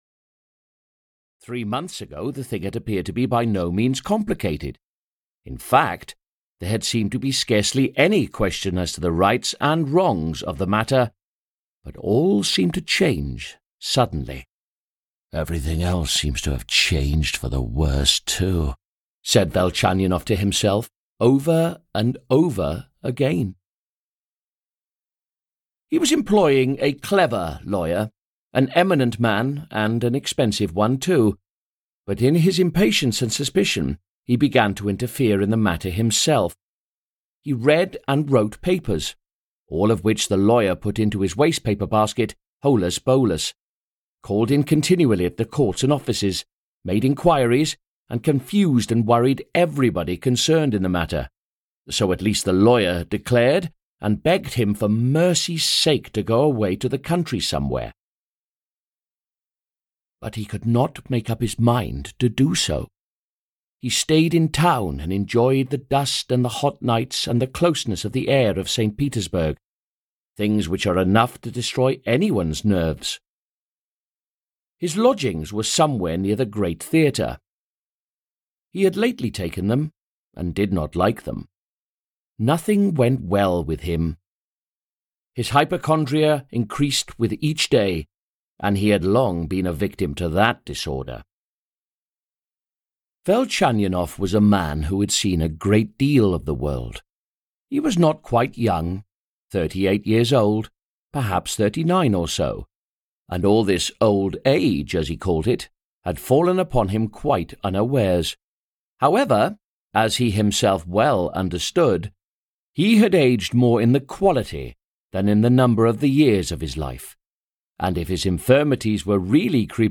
The Eternal Husband (EN) audiokniha
Ukázka z knihy